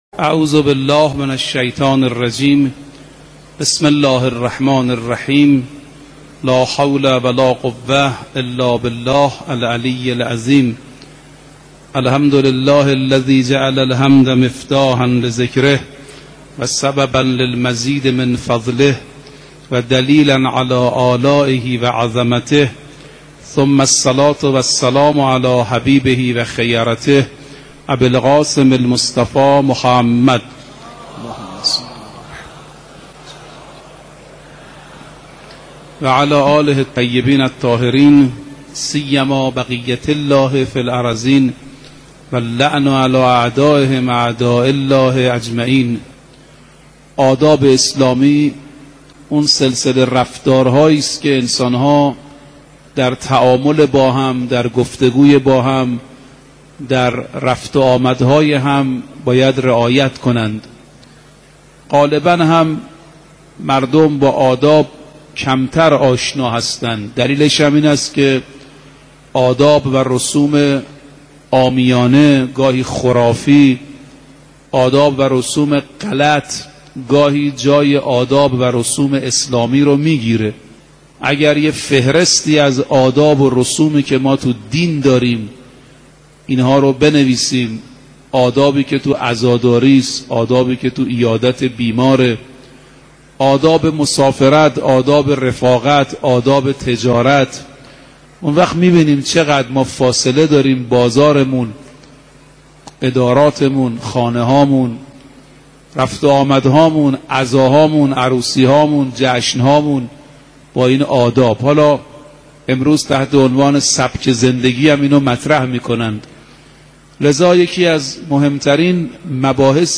آوای معرفت | سخنرانی حجت الاسلام رفیعی _ آداب و رسوم های اسلامی
آداب و رسوم های اسلامی موضوع سخنرانی حجت الاسلام دکتر رفیعی در آوای معرفت هیات رزمندگان اسلام.
سخنرانی-حجت-الاسلام-رفیعی_آداب-و-رسوم-های-اسلامی.mp3